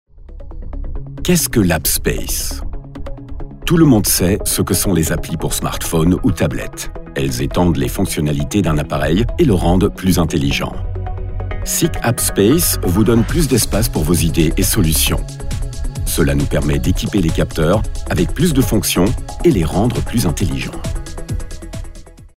Dank seiner angenehmen Tonlage, nicht zu tief und auch nicht zu hell, ist er sehr flexibel einsetzbar.
Imagefilm